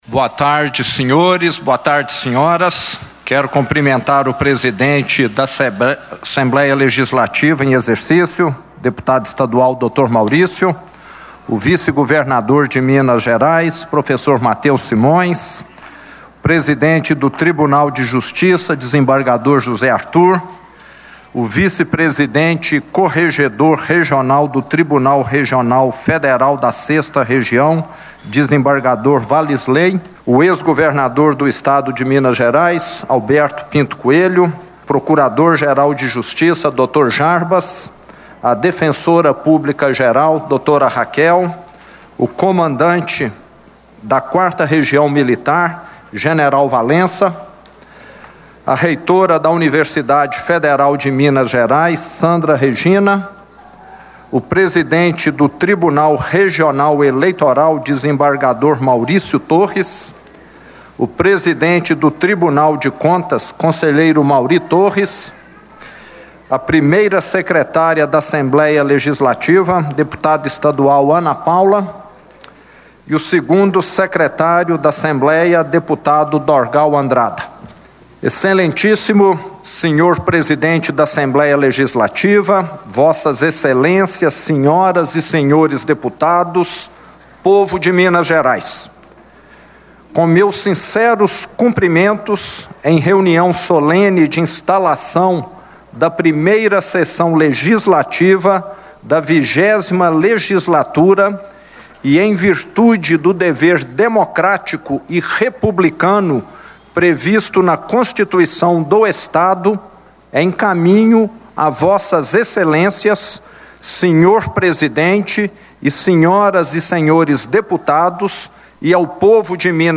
Na cerimônia de posse dos deputados da 20ª legislatura, o governador de Minas fez rápido balanço da situação dos cofres de Minas tendo como desafio alcançar o equilíbrio fiscal.
Discursos e Palestras